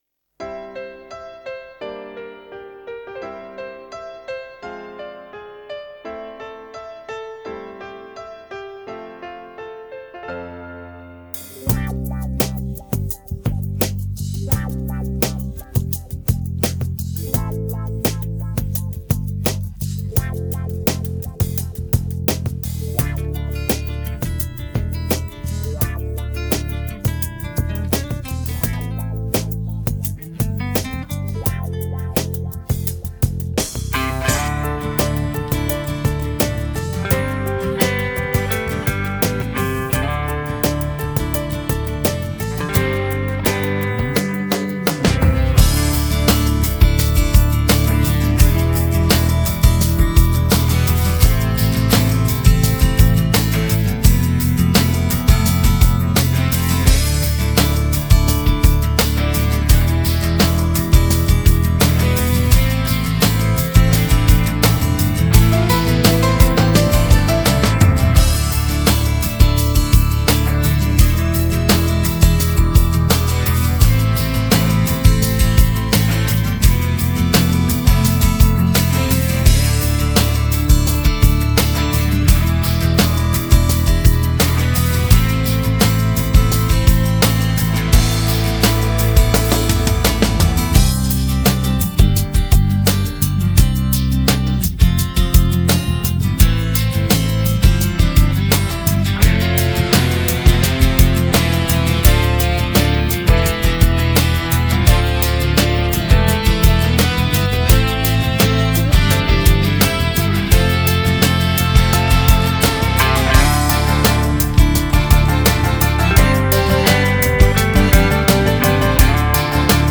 Качественный минус